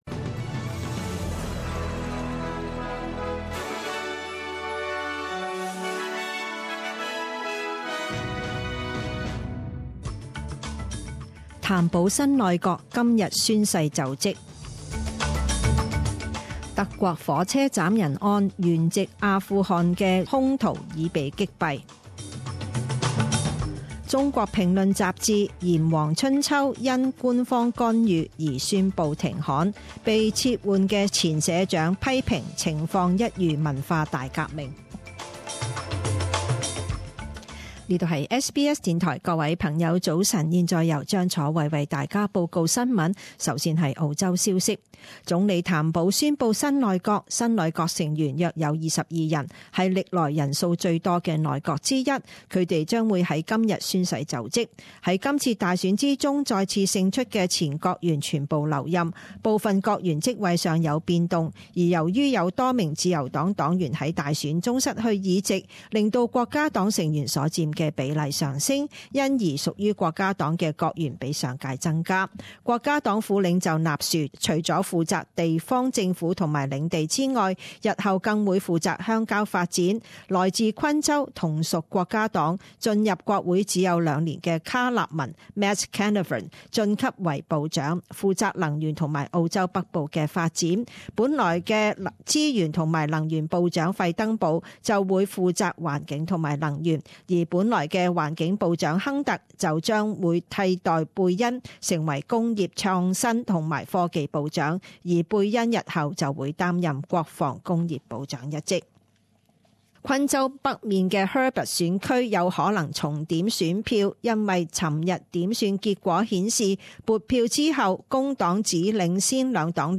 七月十九日十點鐘新聞報導